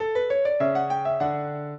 piano
minuet13-12.wav